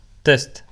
Example of the short piece of speech signal:
Speech      speech
Speech.wav